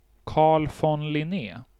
2. ^ Swedish pronunciation: [ˈkɑːɭ fɔn lɪˈneː]